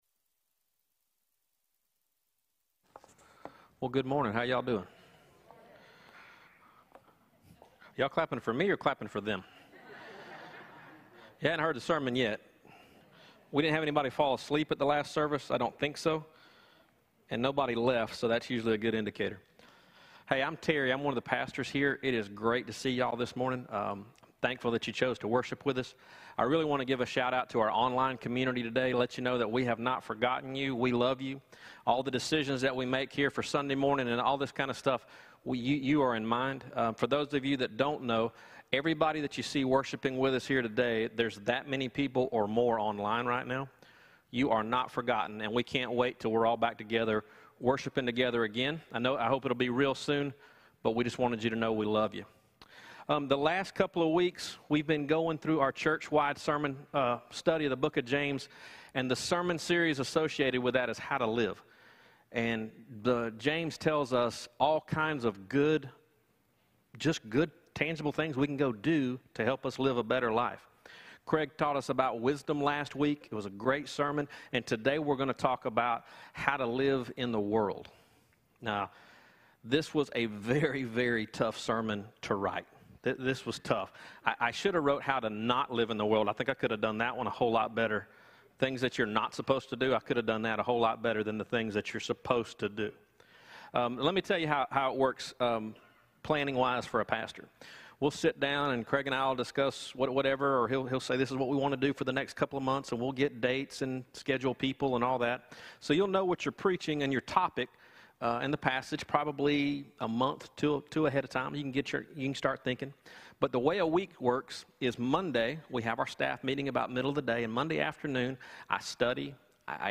Sermon-audio-11.1.20.mp3